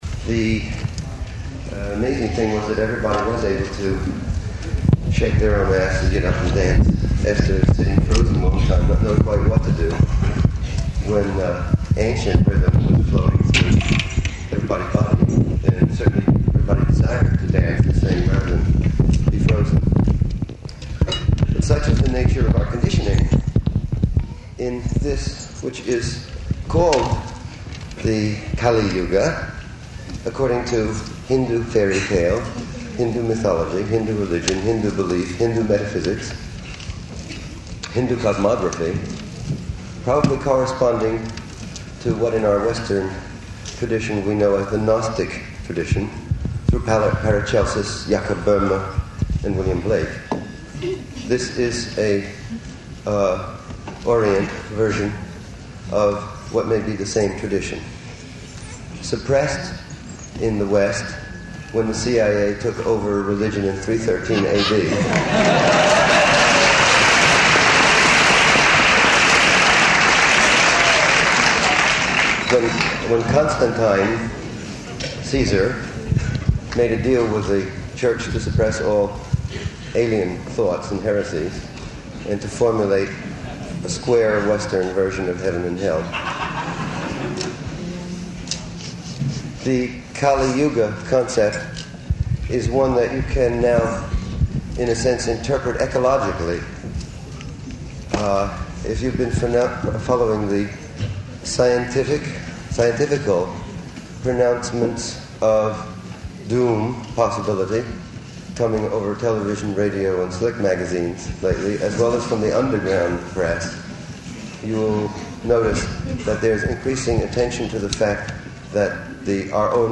Lecture with Allen Ginsberg at Ohio State University
Type: Lectures and Addresses